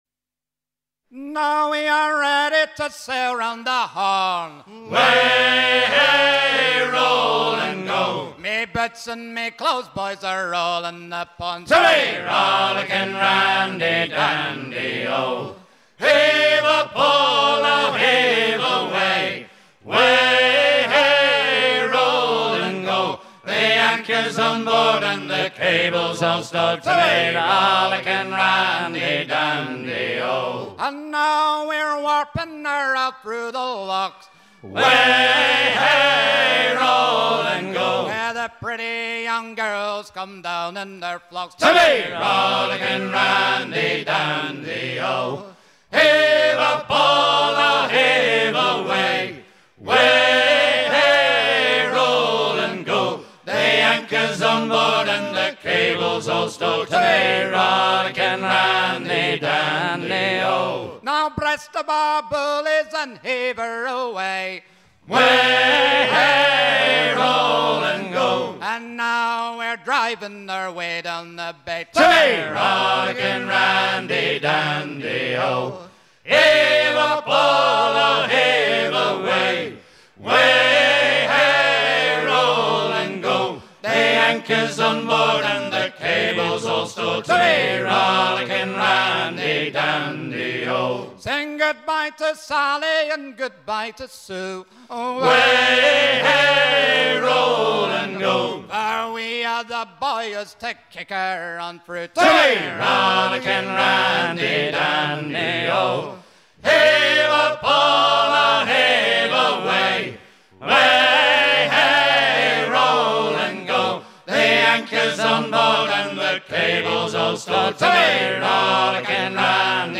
chant apprécié des cap-horniers
à virer au cabestan